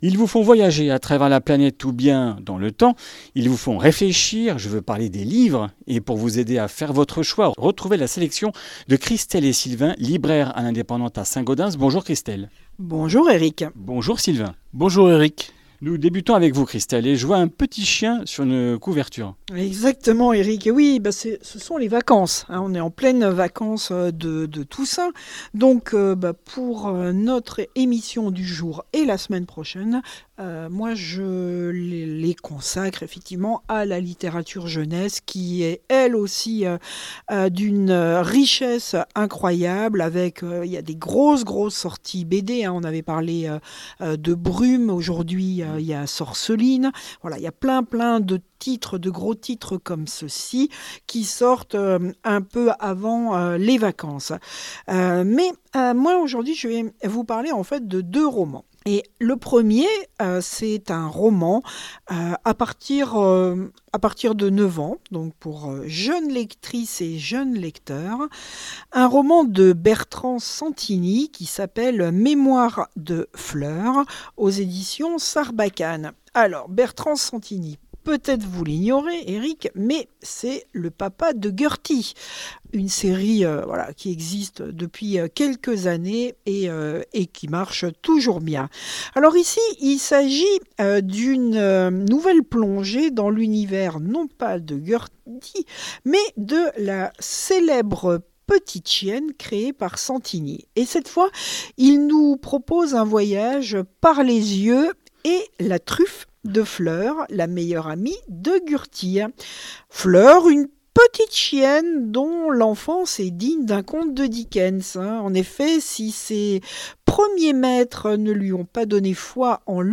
Comminges Interviews du 24 oct.